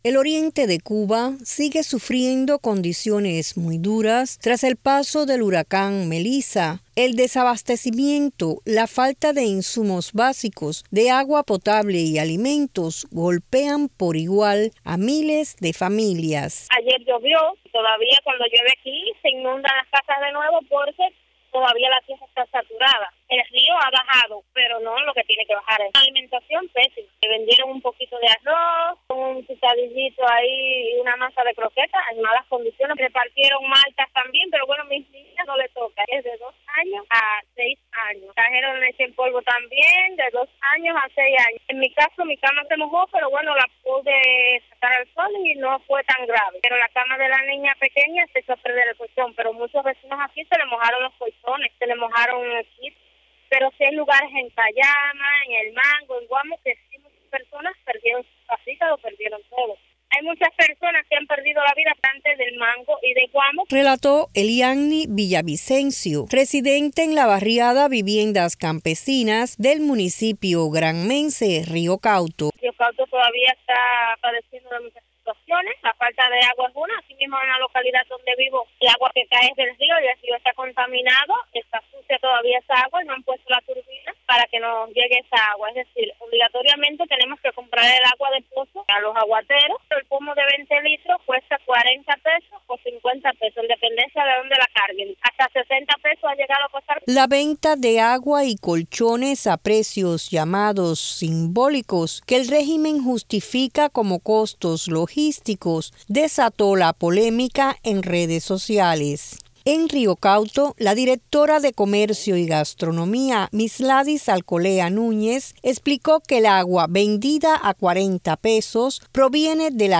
Una residente de ese municipio cuenta en qué condiciones está la mayoría de los afectados, dos semanas después del paso del huracán Melissa.